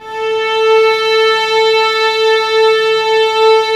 Index of /90_sSampleCDs/Roland - String Master Series/STR_Vlns 6 p-mf/STR_Vls6 mf slo